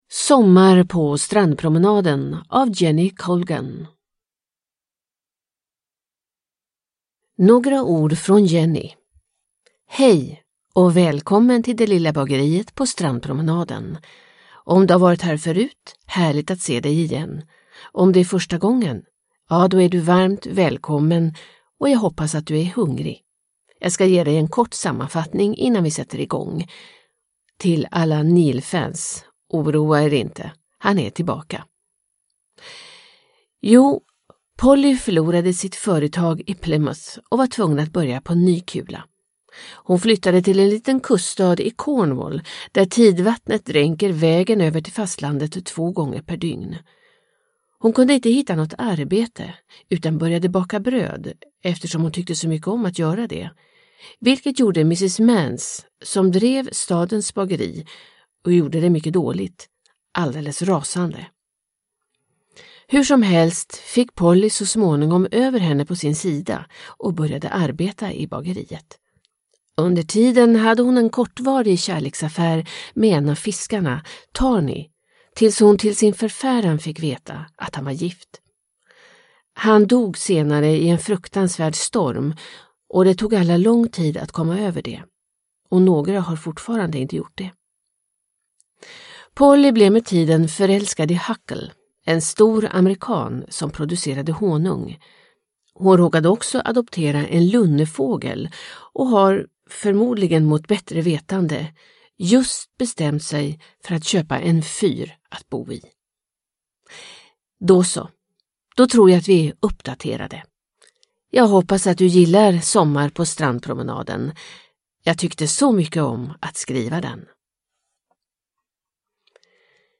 Sommar på strandpromenaden – Ljudbok – Laddas ner